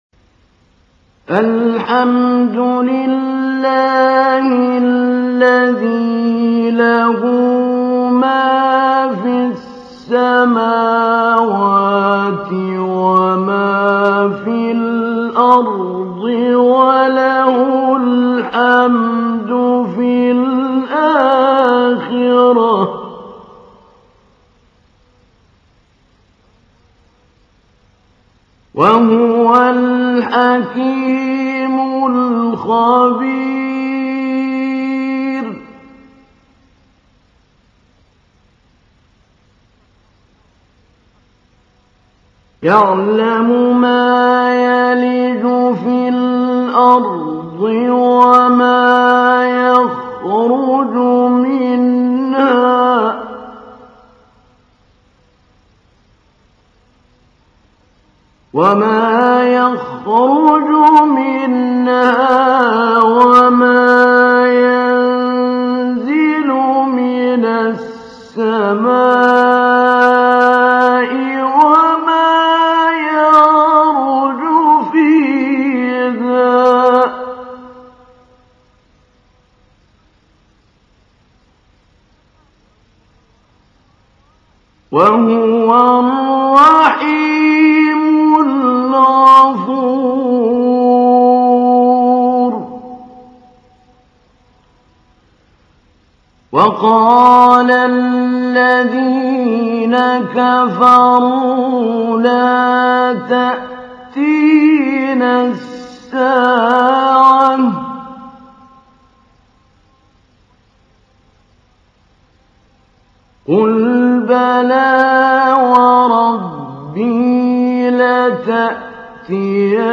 تحميل : 34. سورة سبأ / القارئ محمود علي البنا / القرآن الكريم / موقع يا حسين